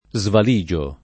vai all'elenco alfabetico delle voci ingrandisci il carattere 100% rimpicciolisci il carattere stampa invia tramite posta elettronica codividi su Facebook svaligiare v.; svaligio [ @ val &J o ], -gi — fut. svaligerò [ @ vali J er 0+ ]